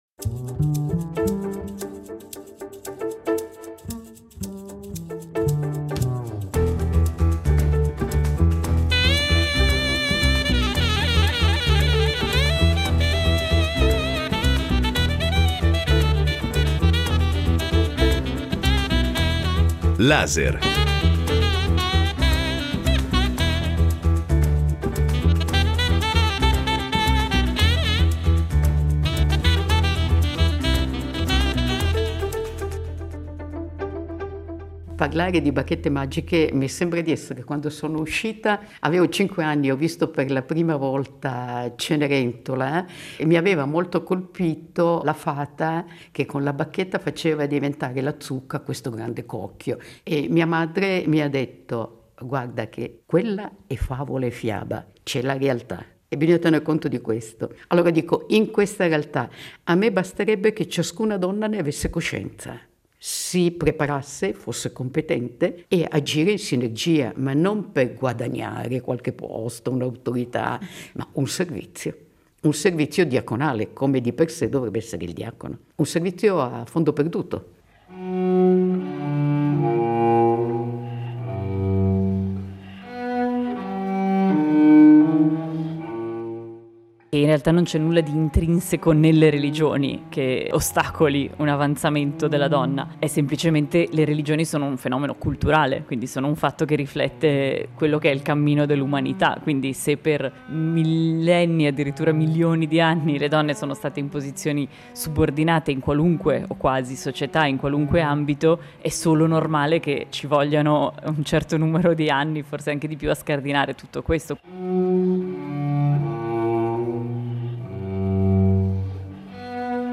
LASER dà la parola a tre protagoniste delle vite religiose cattolica, musulmana ed ebraica, seguendole nella loro giornata, affrontando con loro i temi e le riflessioni che fanno parte della loro esperienza, della loro missione e della loro scelta di vita.